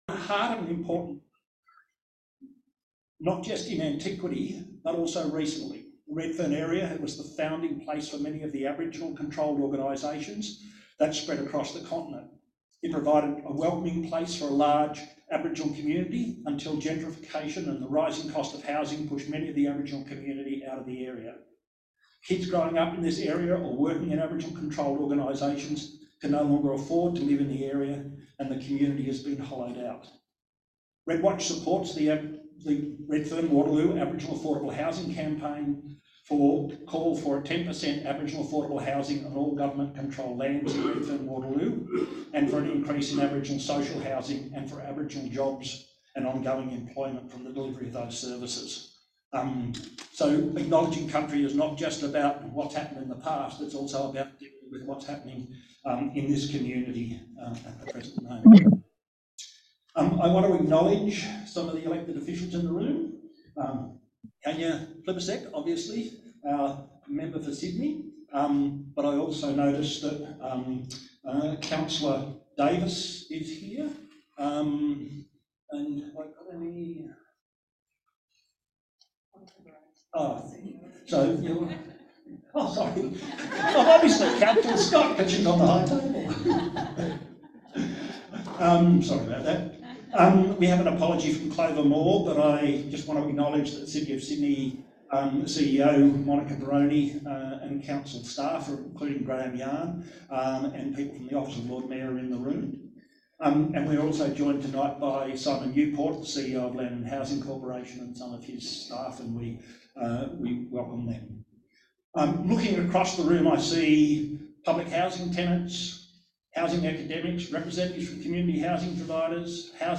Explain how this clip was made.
Audio of REDWatch Federal Government’s Housing Policy Agenda Forum of 5 June 2023